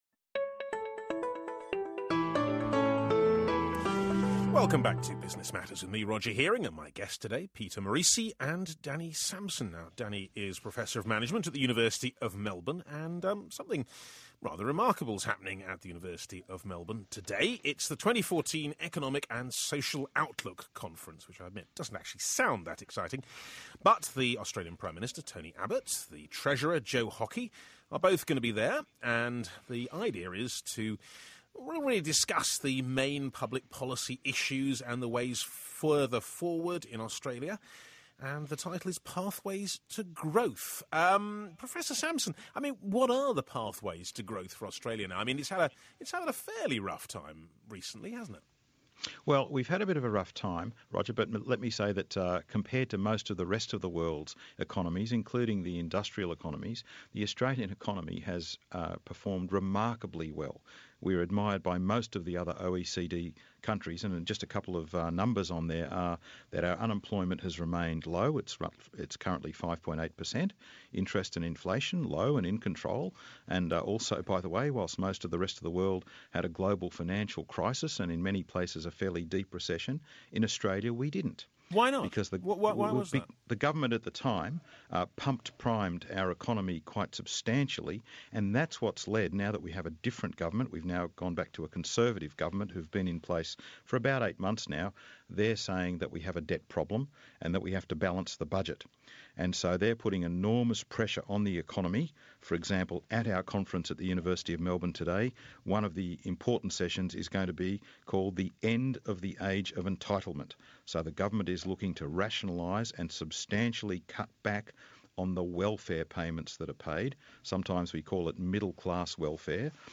spoke to the BBC about the Economic and Social Outlook Conference hosted by the Melbourne Institute and The Australian this week.